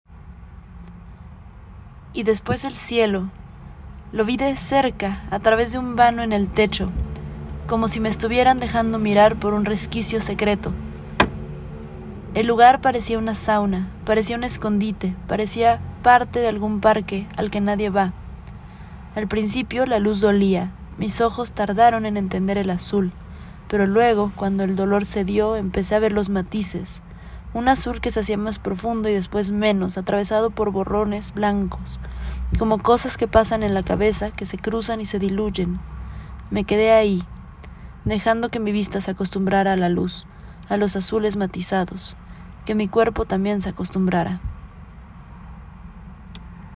Cada fragmento fue escrito como un flujo de conciencia, grabado en audio con mi voz y acompañado por imágenes específicas, evocadas directamente por lo que se cuenta o añadidas por asociación libre.